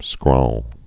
(skrôl)